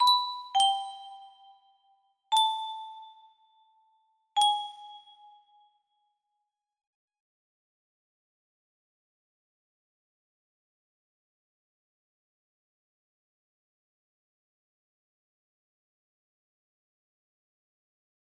Unknsdnlsndflknslkdfnown Artist - Untitled music box melody